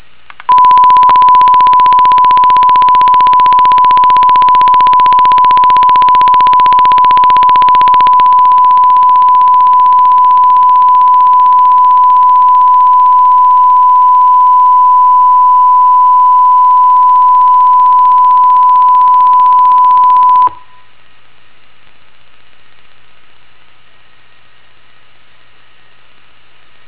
基本上、似乎我在 IN1L 和 IN2L 上采样的数字化音频上有一个快速咔嗒声。
附件中有三个音频文件、我的麦克风在其中录制1kHz 的声音。
在这三种模式中、都是1kHz 的记录。
所有三个录音都是通过使用扬声器旁边的麦克风播放我的 PC 扬声器的1kHz 声音来完成的。
文件2： LOUD_1kHz.WAV (内容虽有争议但声音很大)：
LOUD_5F00_1kHz.WAV